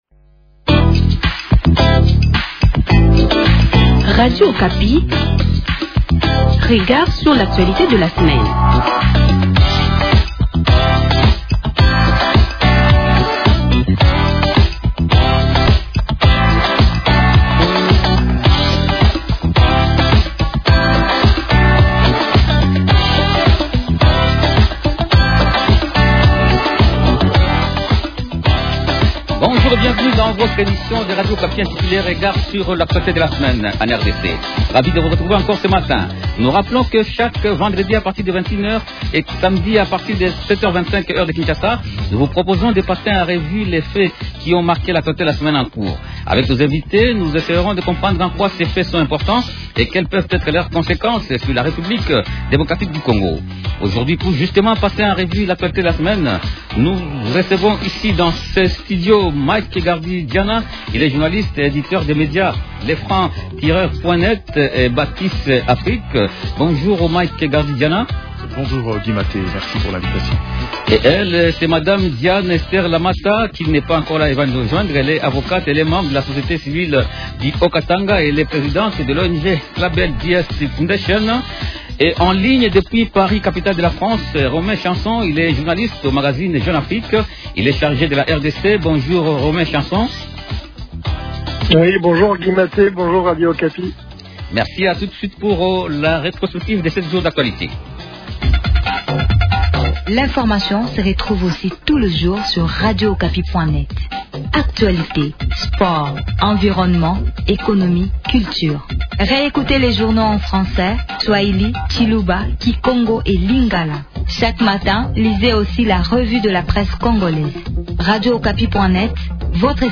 Trois sujets principaux au menu de cette émission :